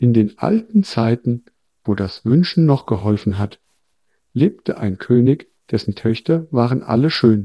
Added wavegrad samples (training in progress)